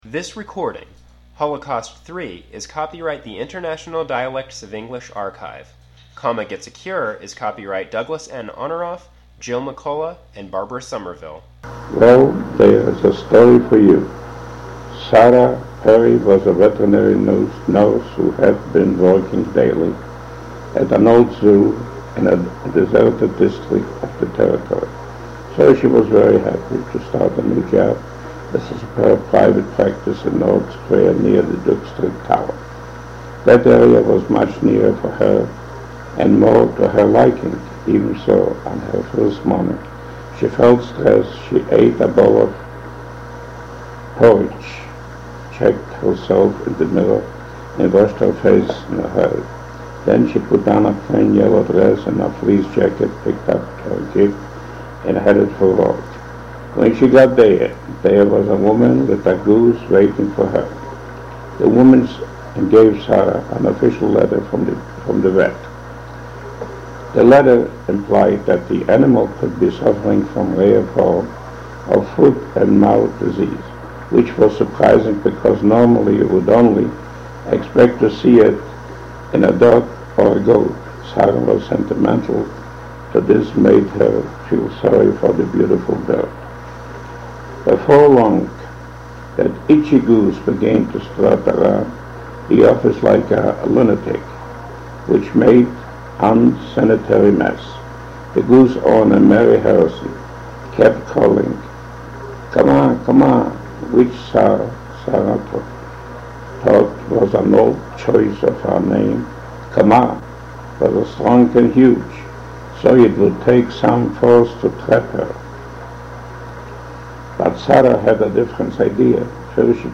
Listen to Holocaust 3, a recording of an 88-year-old man, born in Poland, who survived the Holocaust during World War II.